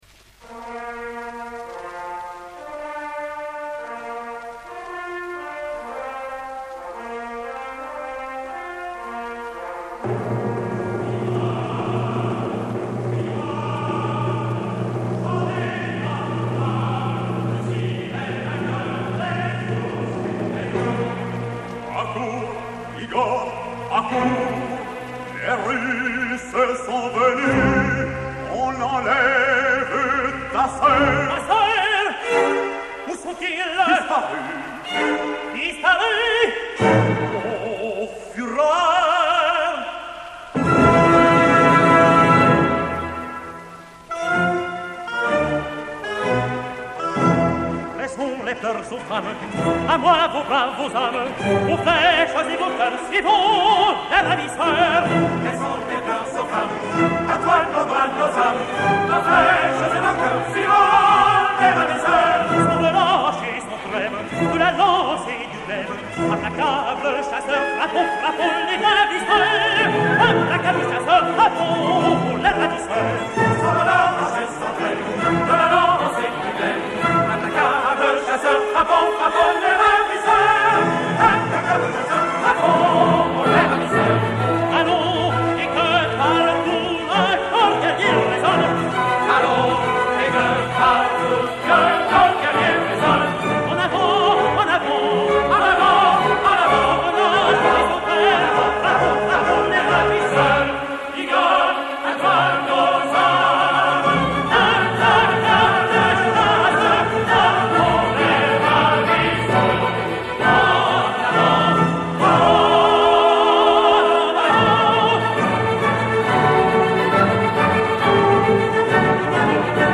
Extraits enregistrés en 1958 (révision musicale d'Henri Büsser) :
Chœurs et Orchestre National de la Radiodiffusion Française dir. Georges Tzipine (chef des chœurs René Alix)
03. Sérénade (Le Jeune Bulgare)